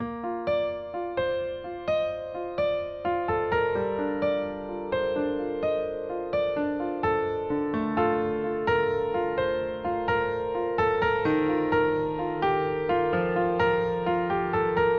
钢琴LOVE 128BPM
Tag: 128 bpm Acoustic Loops Piano Loops 2.53 MB wav Key : Unknown